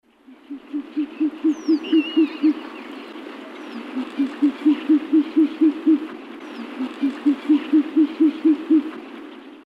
Звуки филина
На этой странице собраны звуки филина — мощные крики, уханье и другие голосовые проявления этой величественной птицы.
Тайный голос филина в дикой природе